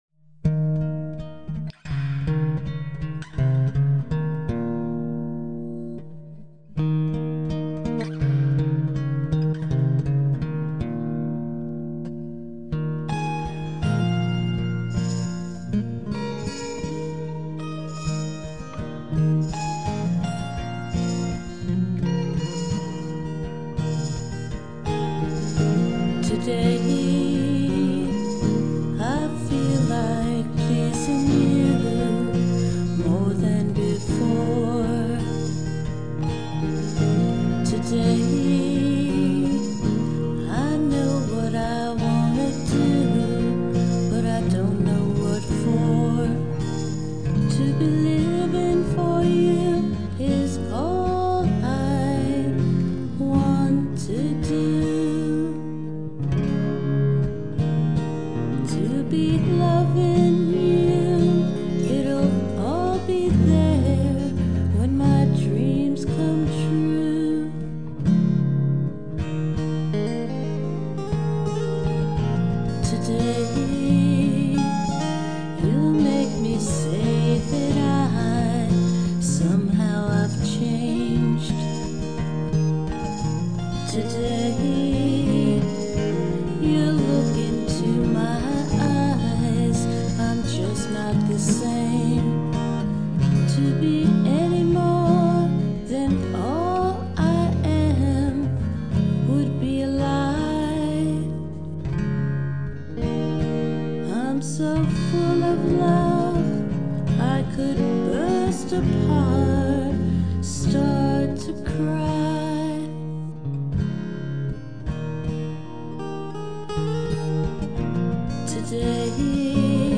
Vocals
Fender, Martin acoustic, lame ass
tamborine on synthesizer